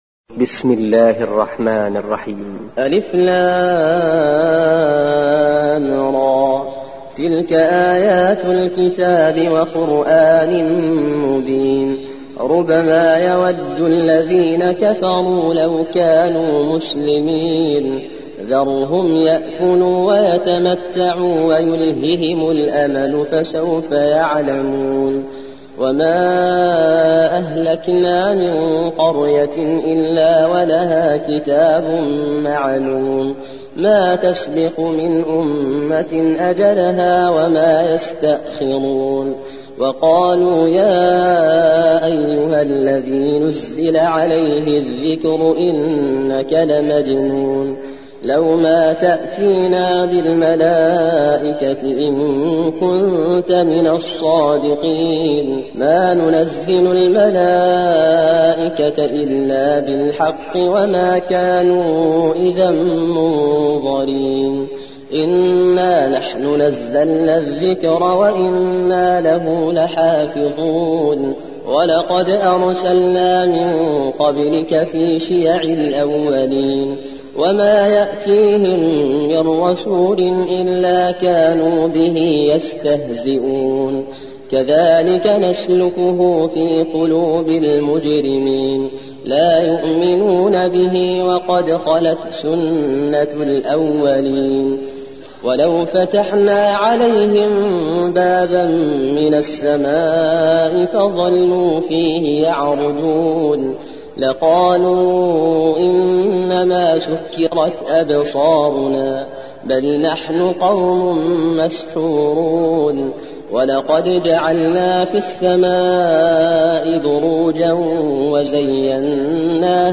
15. سورة الحجر / القارئ